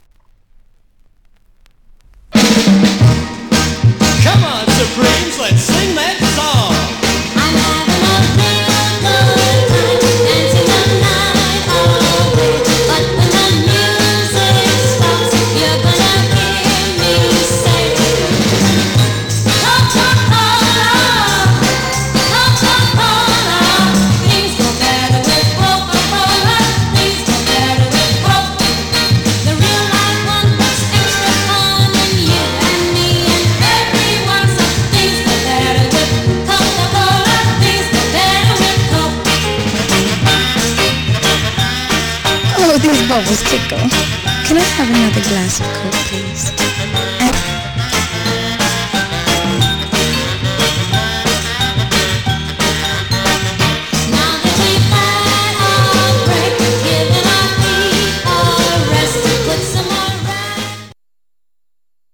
Barely played, Some surface noise/wear
Mono